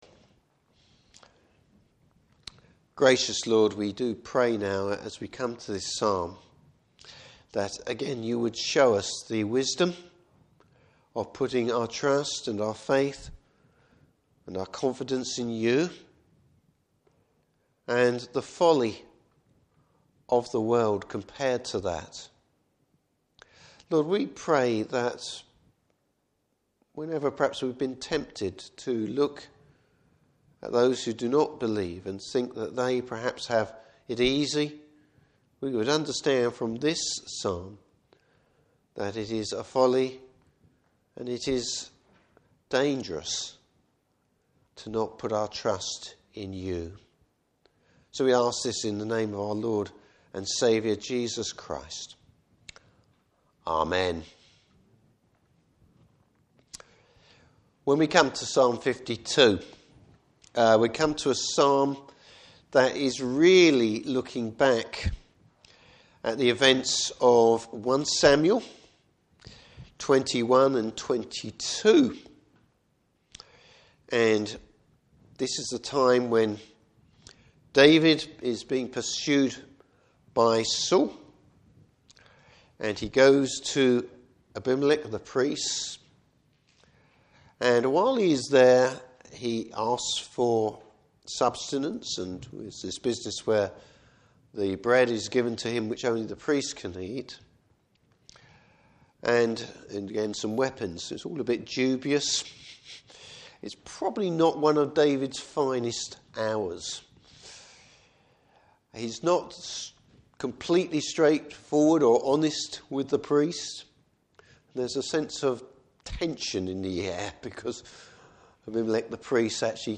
Service Type: Evening Service The contrast between the wicked and those who are the Lord’s. Topics: Fellowship with God.